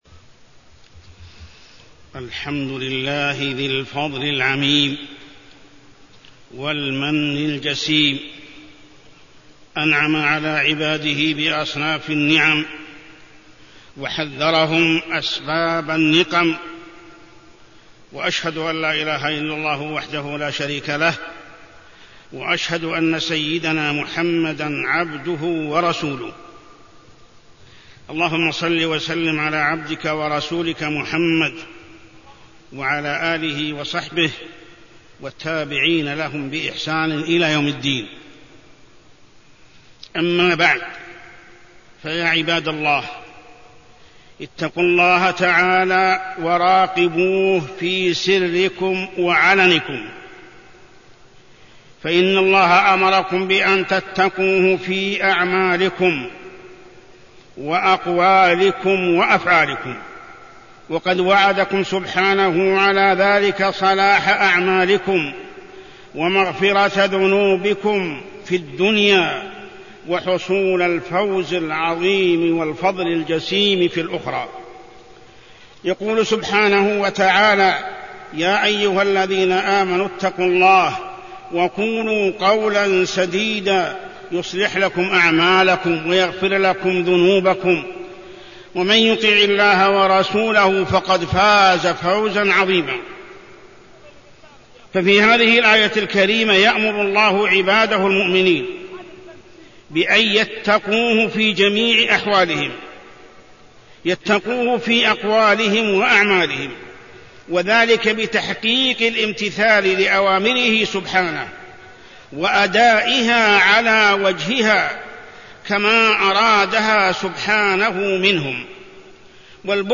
تاريخ النشر ٧ جمادى الآخرة ١٤٢٣ هـ المكان: المسجد الحرام الشيخ: محمد بن عبد الله السبيل محمد بن عبد الله السبيل تقوى الله في السر والعلن